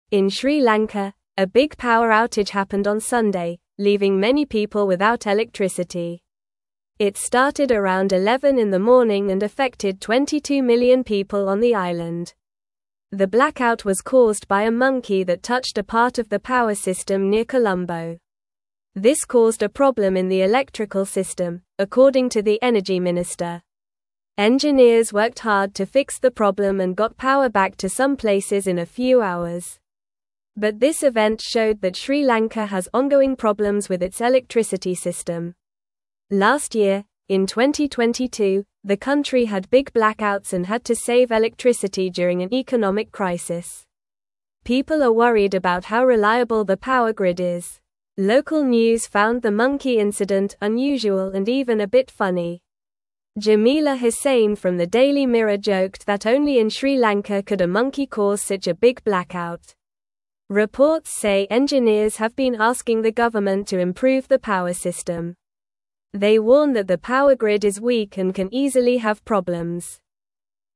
Normal
English-Newsroom-Lower-Intermediate-NORMAL-Reading-Monkey-Turns-Off-Lights-in-Sri-Lanka-for-Everyone.mp3